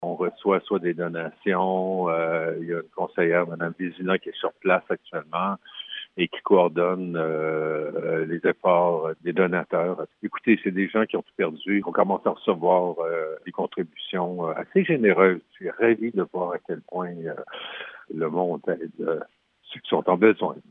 Le Maire d’Abercorn, Guy Favreau